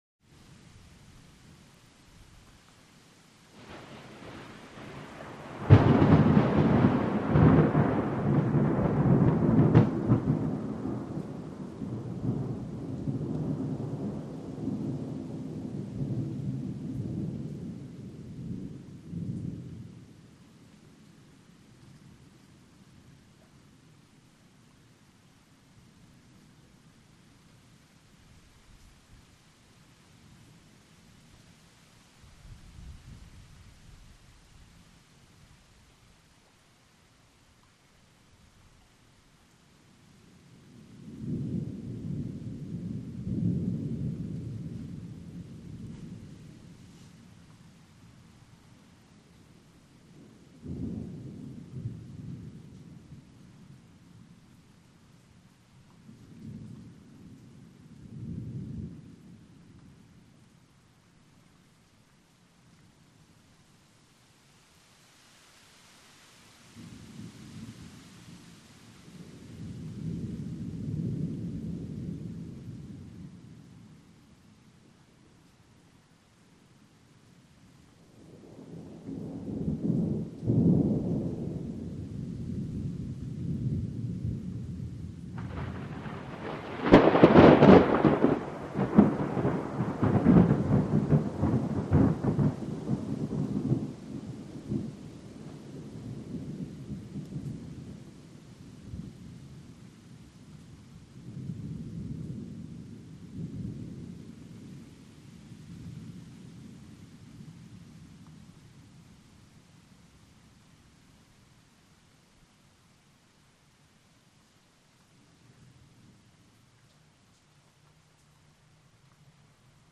Light Rain/Wind
Thunder; Rumbles And Cracks In The Distance With Drizzle Swells And Very Light Wind.